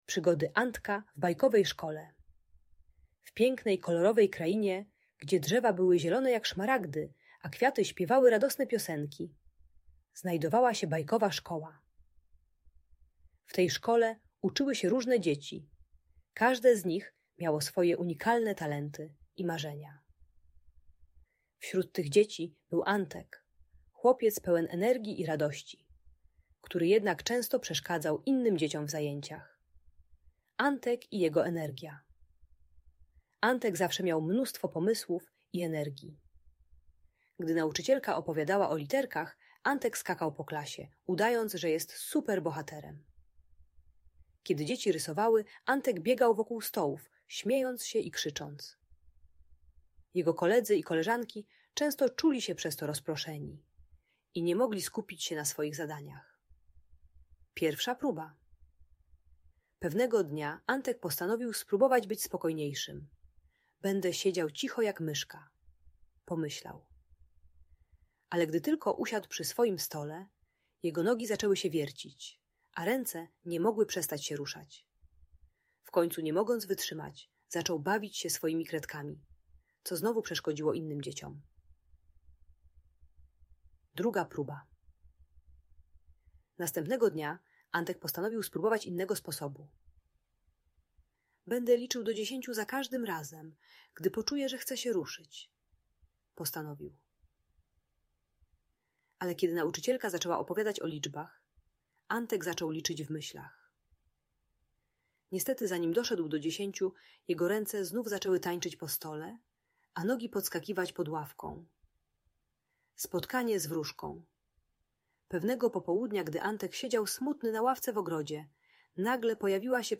Przygody Antka - historia o energii i przyjaźni - Audiobajka